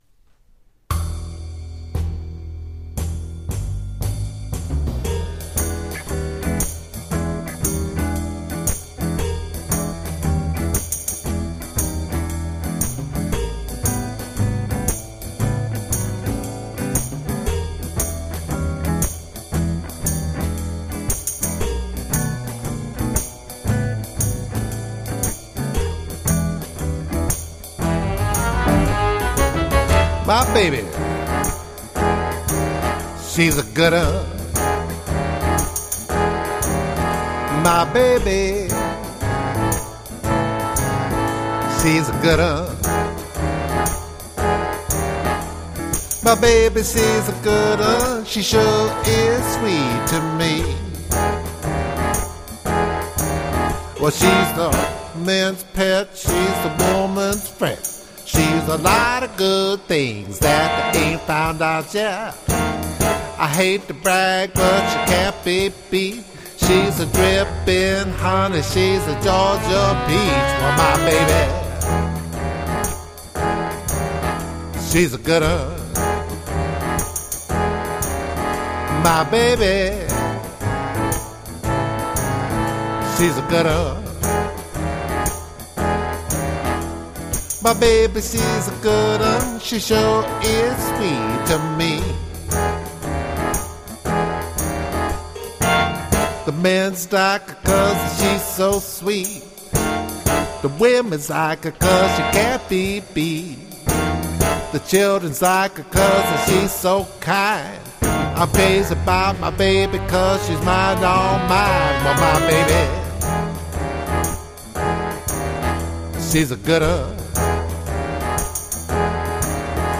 These music files can also be used for live performance.
Blues